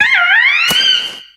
Cri de Ouisticram dans Pokémon X et Y.